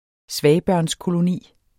Udtale [ ˈsvæjbɶɐ̯nskoloˌniˀ ]